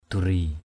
/d̪u-ri:/ (d.) đá quý. gems. mâh pariak duri mH pr`K d~r} vàng bạc đá quý. gold, medal and gems.